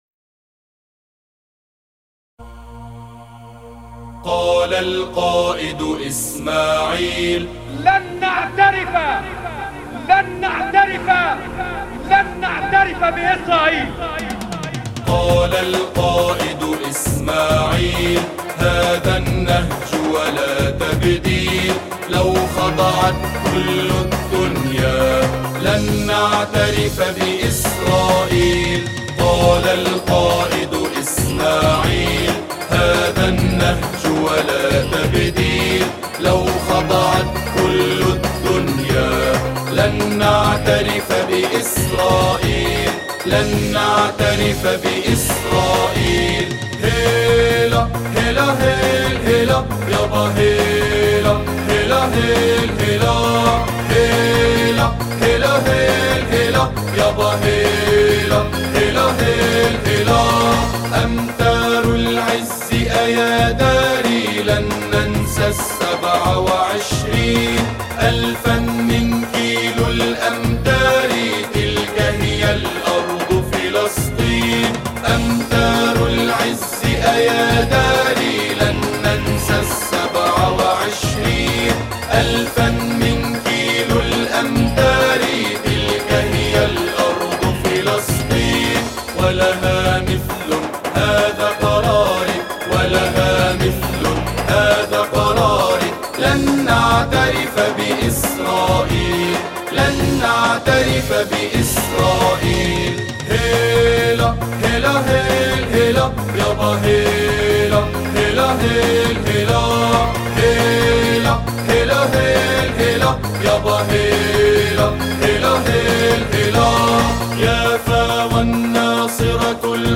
أناشيد فلسطينية... لن نعترف